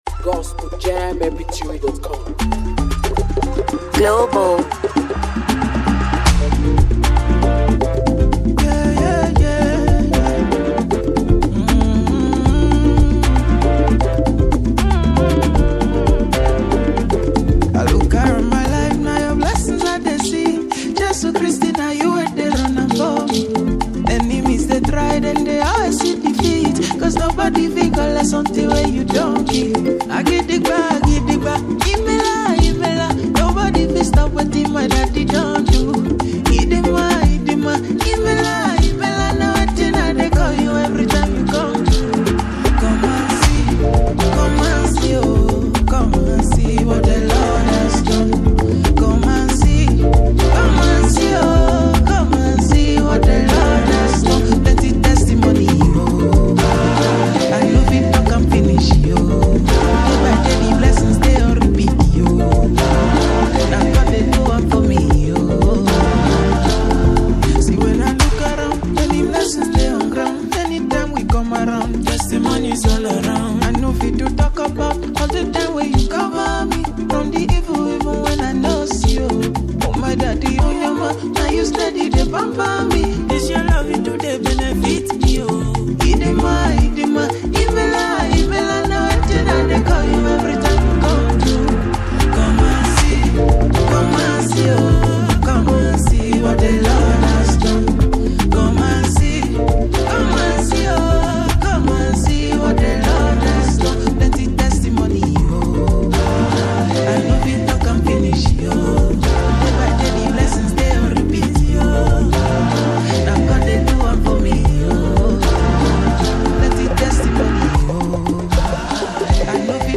Afro Gospel